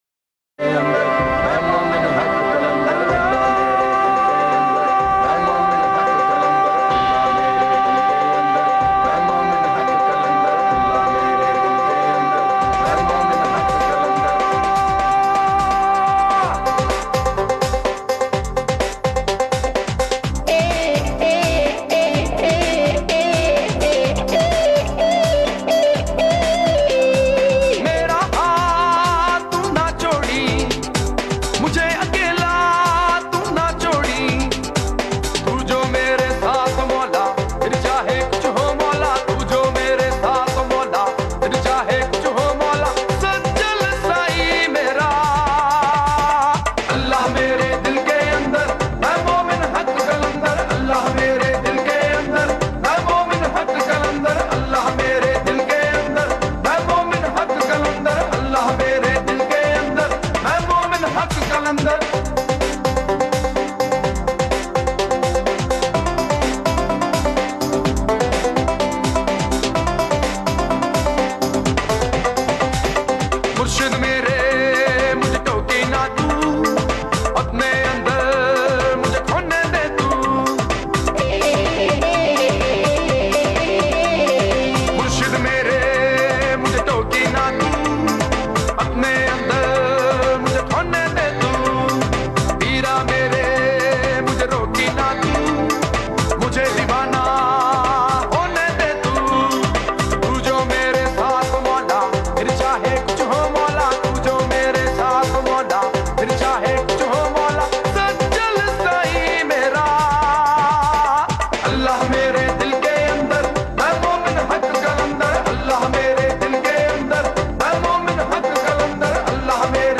Sufi Rock